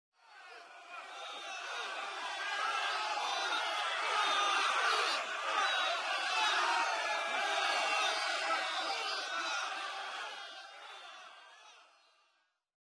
Vox
Hollarin (2).wav